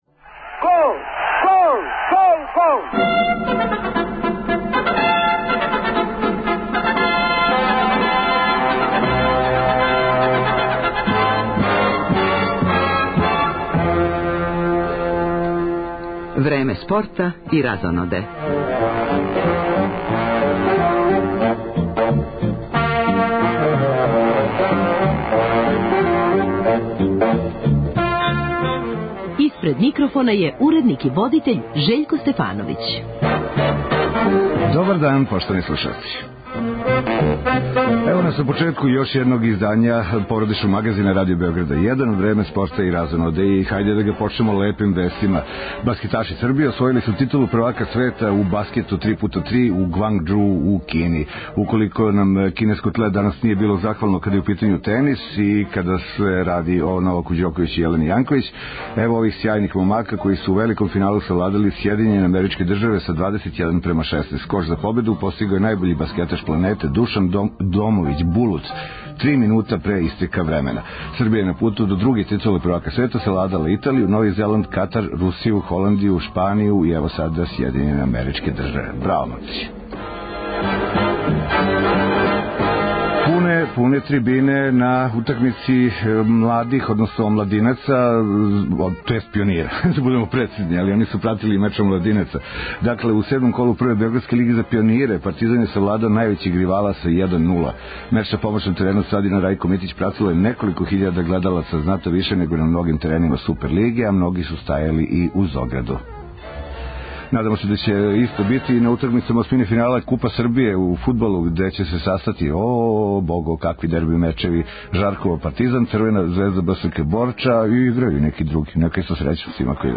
После много година, Зоран Предин наставља рад са својом матичном групом Лачни Франц из Марибора, истина уз нове снаге и нови албум. С Предином данас на ту тему, али и о сарадњи с фамилијом Дедић, концертима који га очекују, дешавањима на спортском плану - јер је, уз остало, и спортски радник - током овог поподнева.